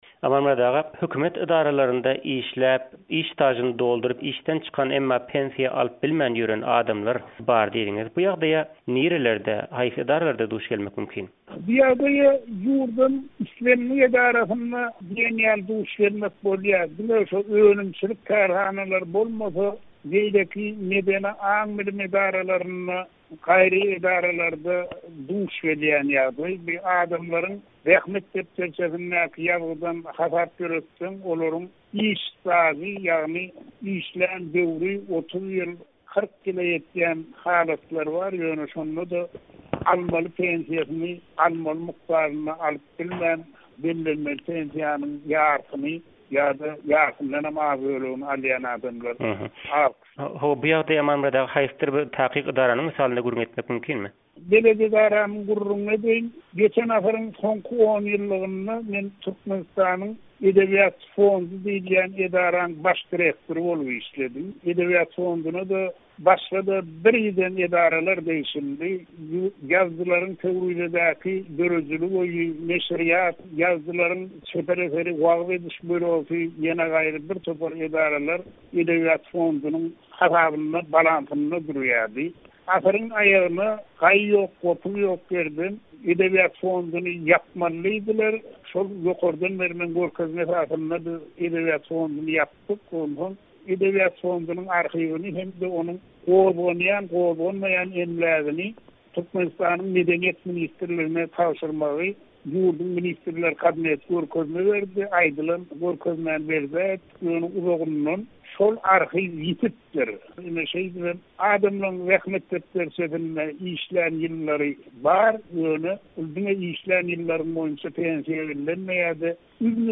söhbetdeş boldy